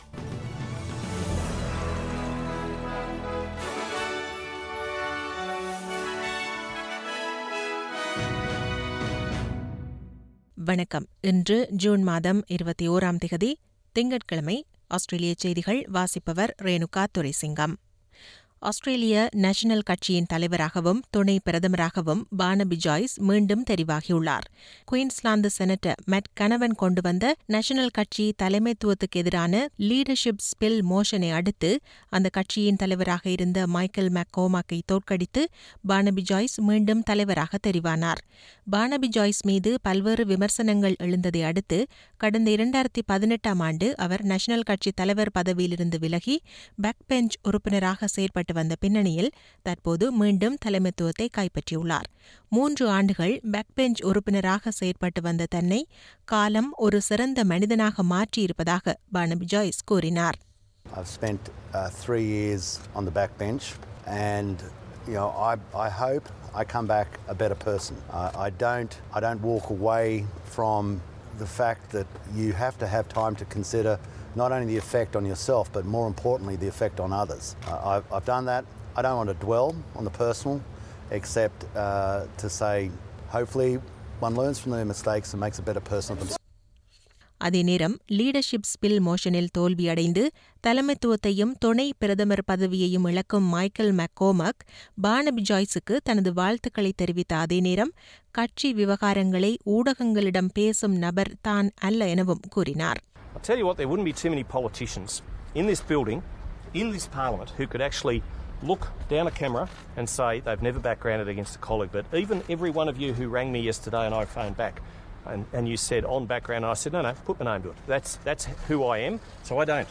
Australian news bulletin for Monday 21 June 2021.